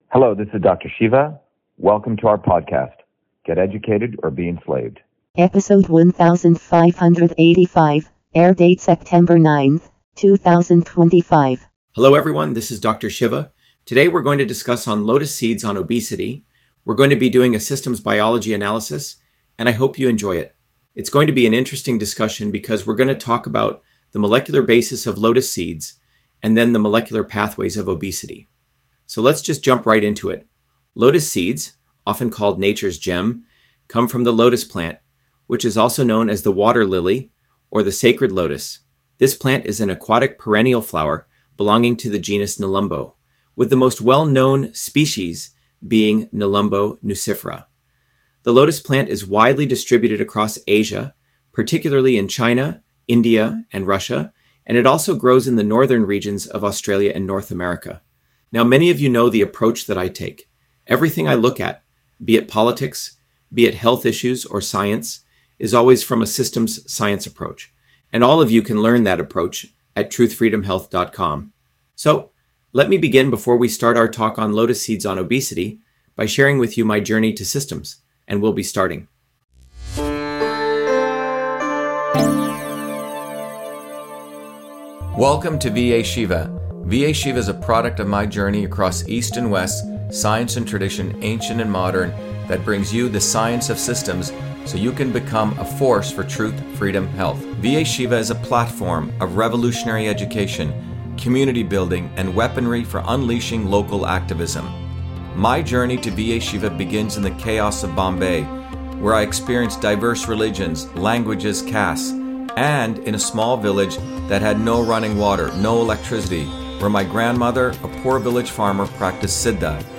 In this interview, Dr.SHIVA Ayyadurai, MIT PhD, Inventor of Email, Scientist, Engineer and Candidate for President, Talks about Lotus Seeds on Obesity: A Whole Systems Approach